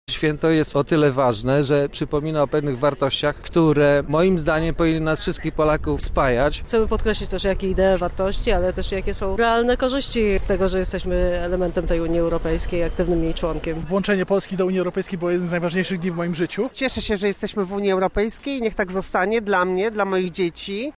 Przybyli w niedzielę (25 marca) na plac Teatralny przypomnieli wartości wspólnotowe, takie jak: tolerancja, solidarność i równość. Zapytaliśmy zgromadzonych o znaczenie tej rocznicy.